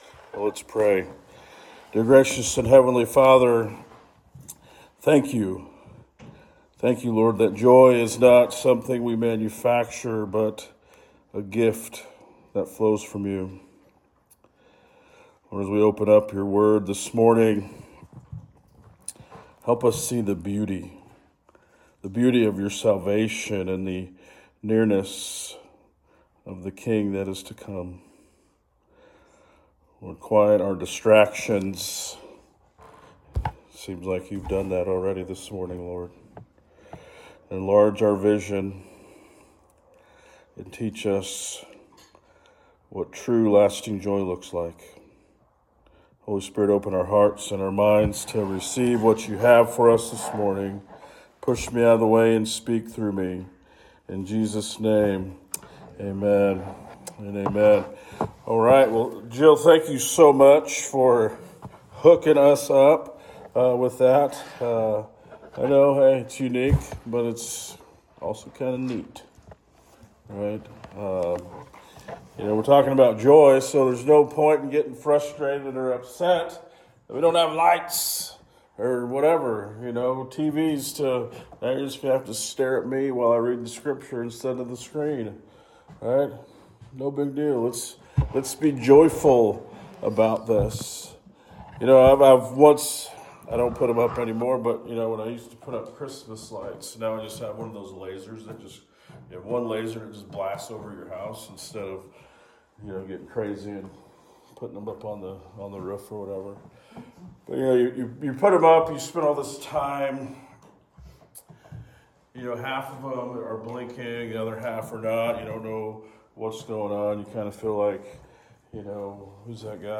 **Audio Only (we lost power 15 minutes before worship began)**What is the difference between being jolly and having true joy?